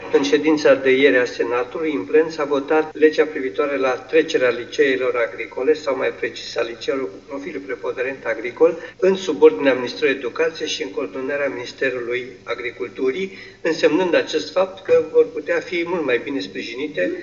Secretarul de stat din cadrul Ministerului Educației, Petru Andea, a declarat astăzi într-o videoconferință cu inspectoratele școlare că elevii se vor putea pregăti mai bine pe partea de practică și vor avea șanse mai mari să își găsească un loc de muncă: